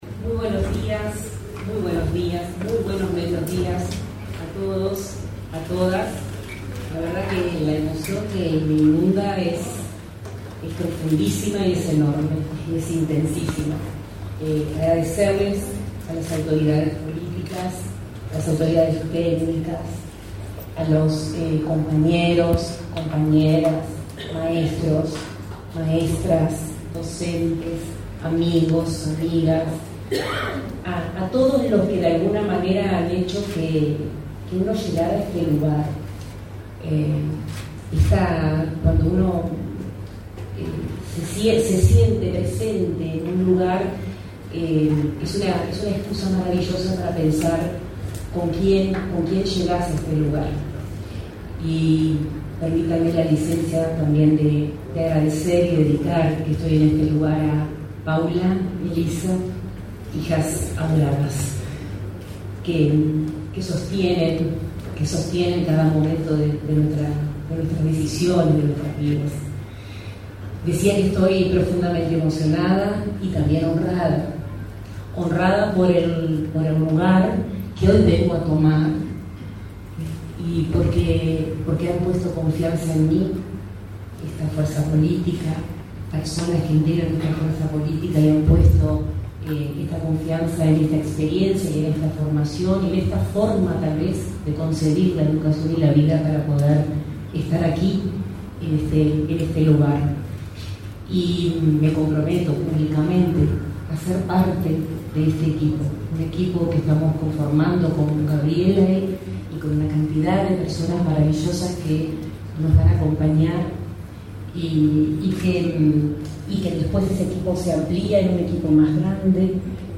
Acto de asunción de las autoridades de la Dirección General de Educación Inicial y Primaria